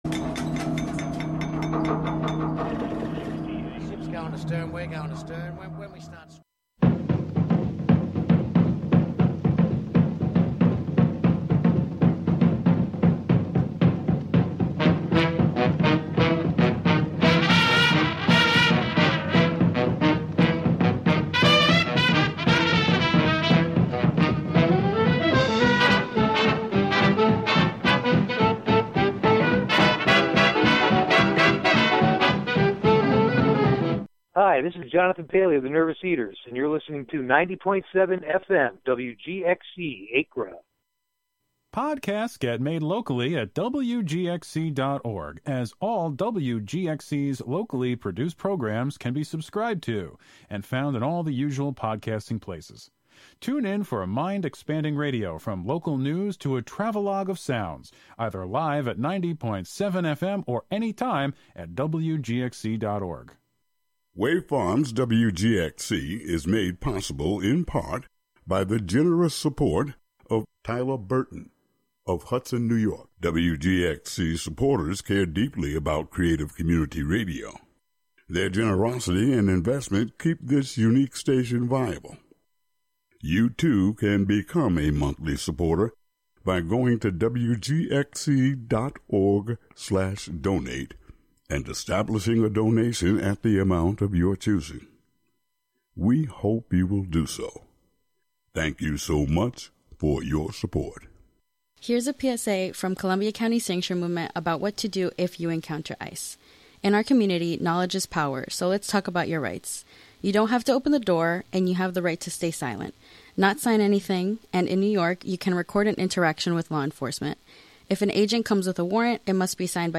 He'll be live in the studio for a conversation.
Live from WGXC's Acra studio, the show features music, field recordings, performances, and interviews, primarily with people in and around the Catskill Mountains of New York.